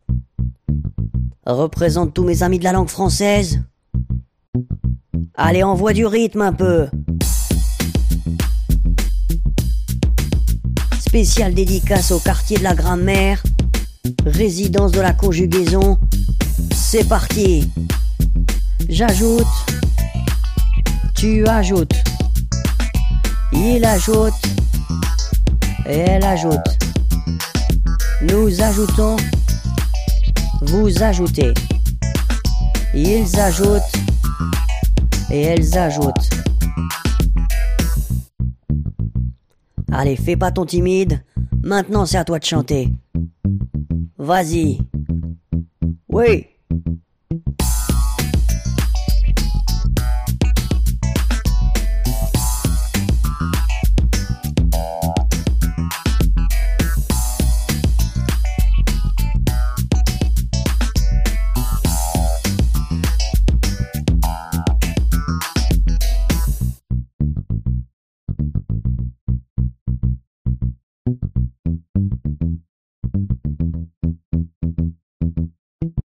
Sing the conjugation # Hip hop